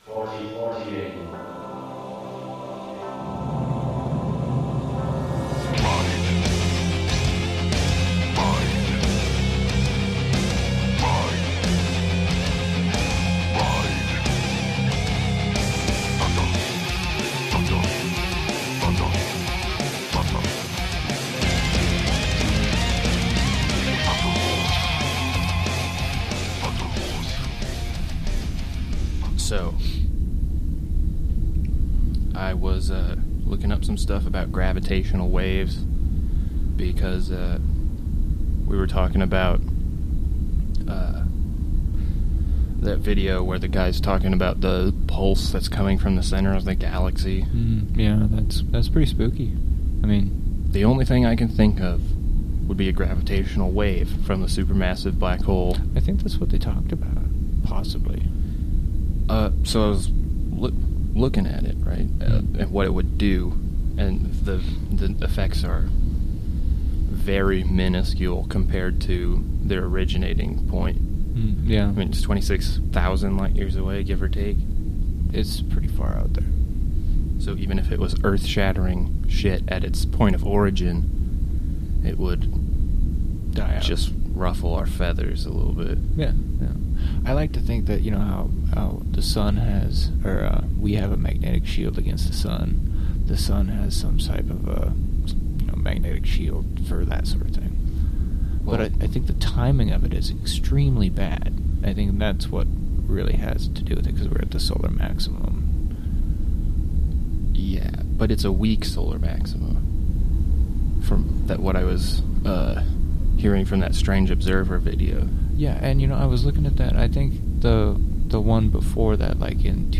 After discovering the first recording was shit due to tragic misconfiguration of equipment, this is the pilot episode of Cryptodome.